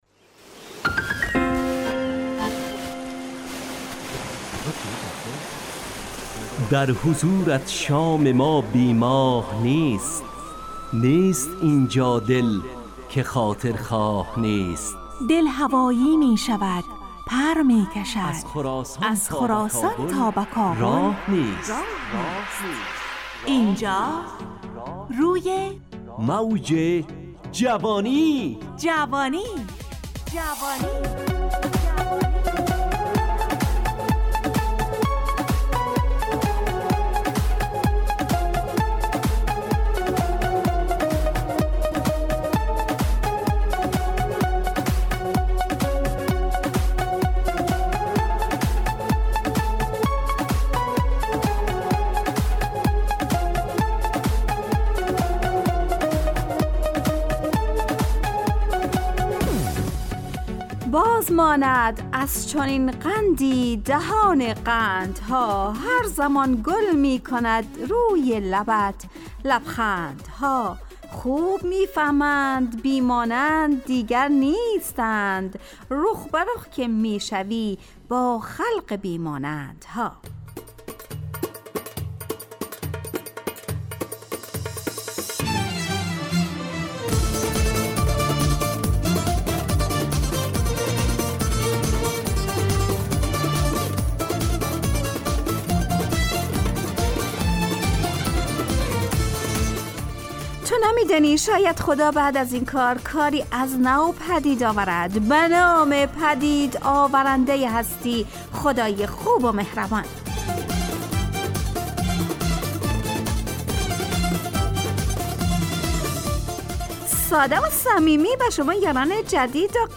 همراه با ترانه و موسیقی مدت برنامه 70 دقیقه . بحث محوری این هفته (گمشده)
روی موج جوانی برنامه ای عصرانه و شاد